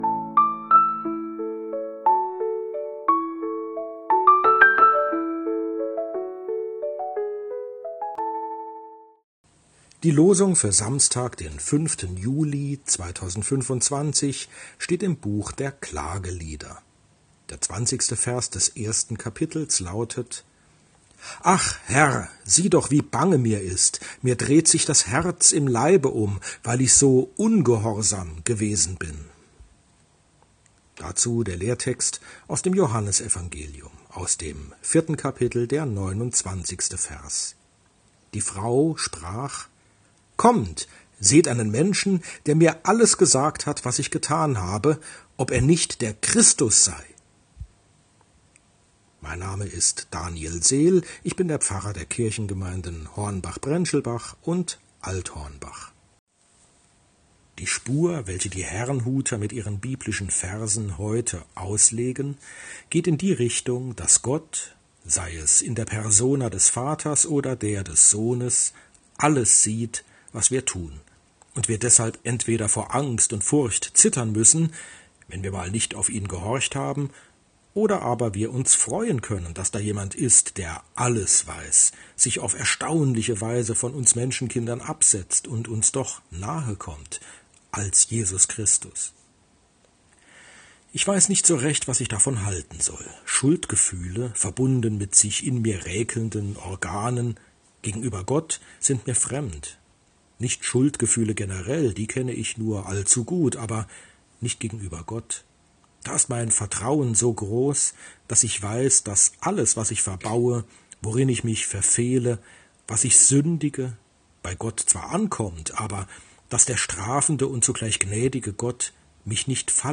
Losungsandacht für Samstag, 05.07.2025